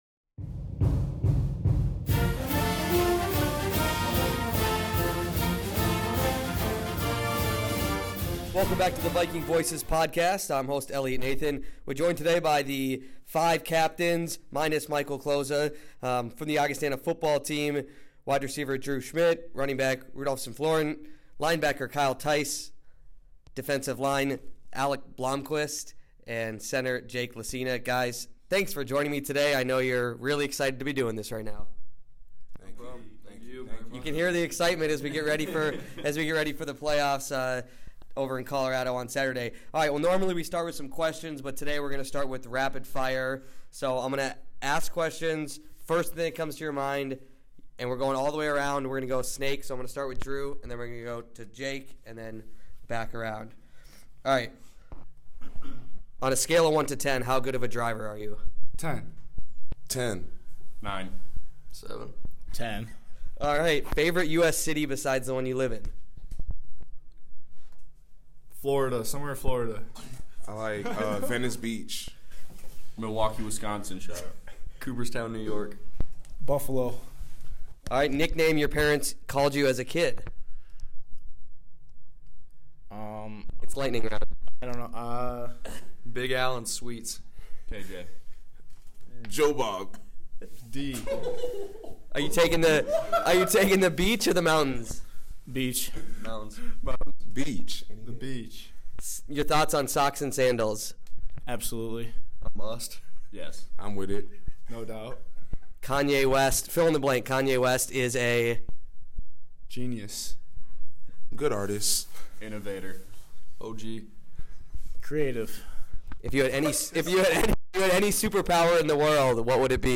Ahead of Saturday’s opening-round playoff game, five of the captains joined the Viking Voices podcast to discuss everything from leadership to style of play and Saturday’s matchup. Of course, we also quizzed the student-athletes with a fun, round-robin lightning game.